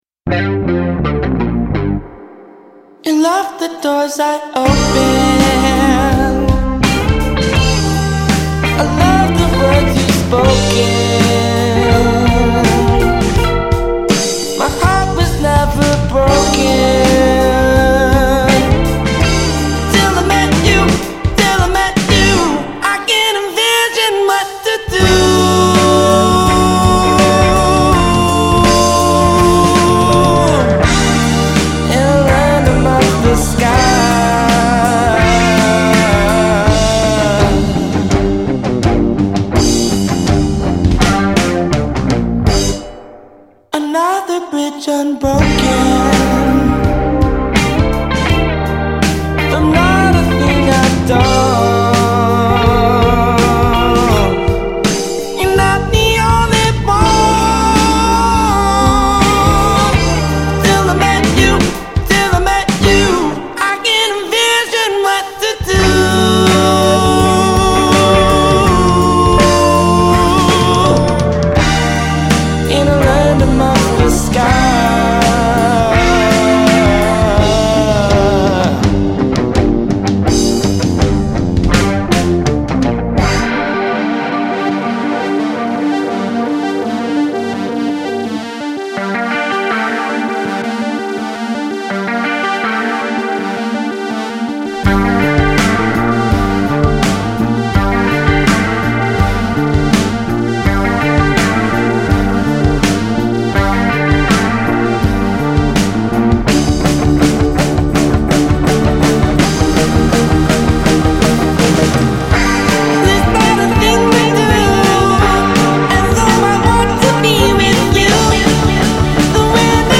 muscular psychedelic 70’s sound
The fierce fretwork